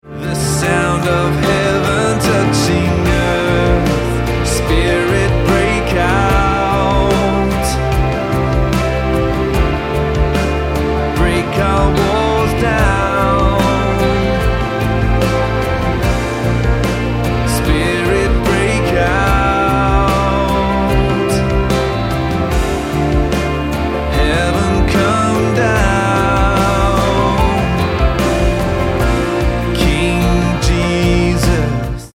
Bb